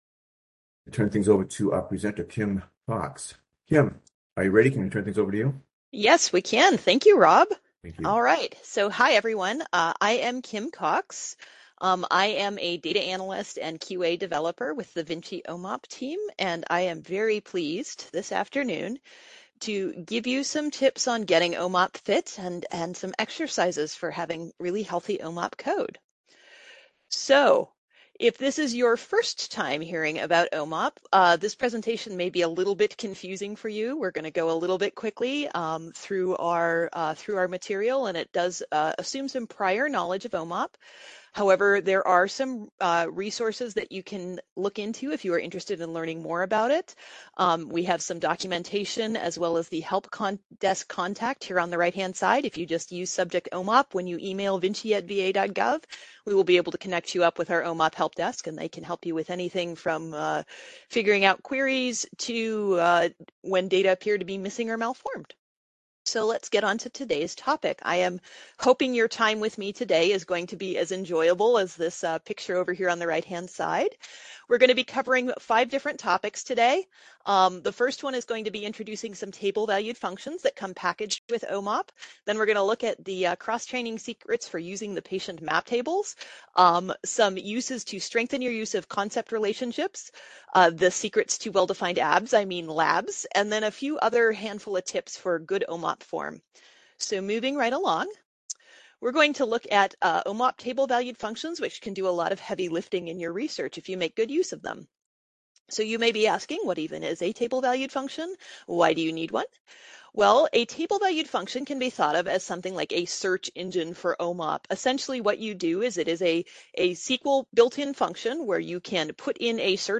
MS Seminar date